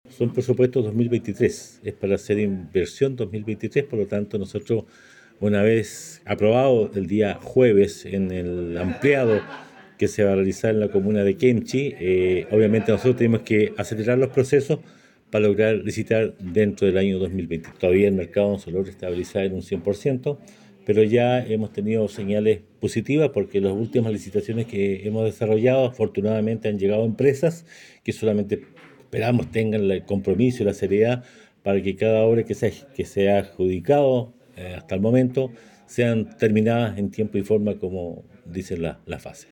El jefe comunal señaló que de ser aprobados estos proyectos se acelerarán los procesos de licitación para poder desarrollarlas durante este 2023, tal como lo indican las bases.